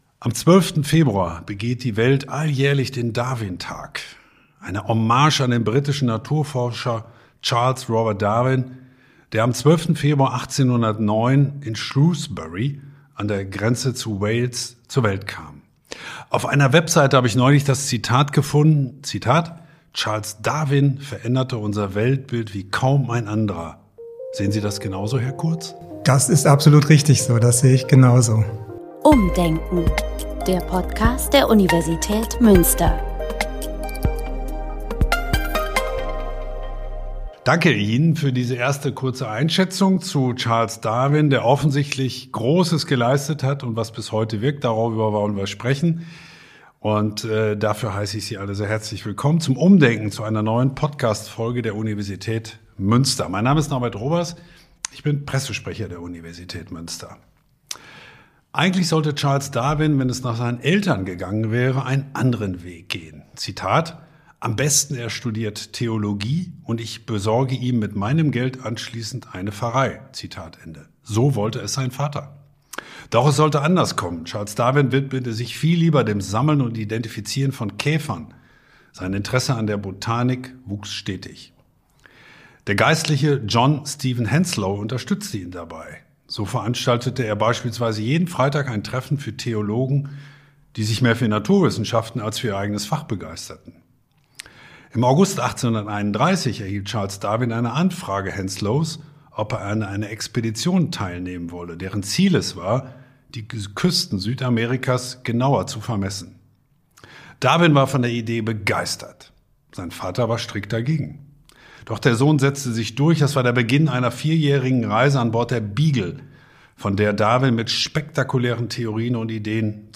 Umdenken – der Podcast der Universität Münster Im Podcast der Universität Münster kommen Wissenschaftlerinnen und Wissenschaftler aus unterschiedlichen Disziplinen zu Wort.